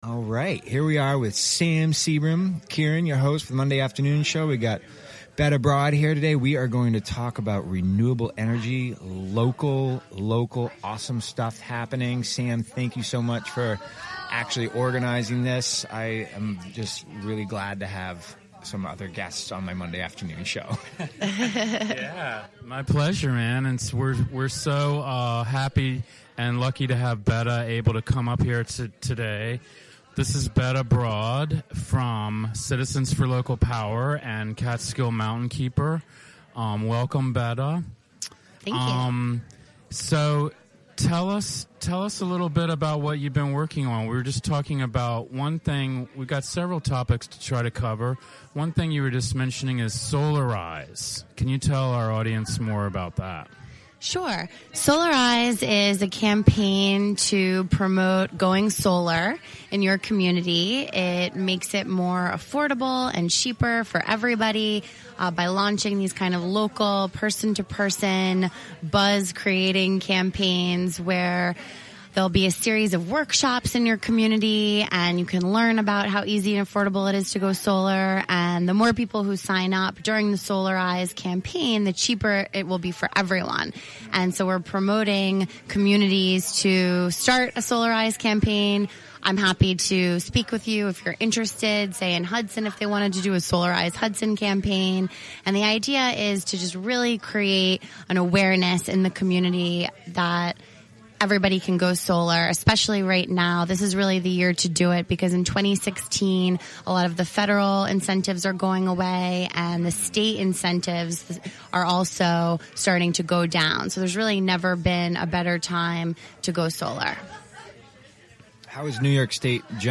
The kitchen cabinet meets weekly in Rosendale, Ulster Co. Recorded during a live broadcast at the Spotty Dog in Hudson during the For WGXC! Winter 2015 Pledge Drive.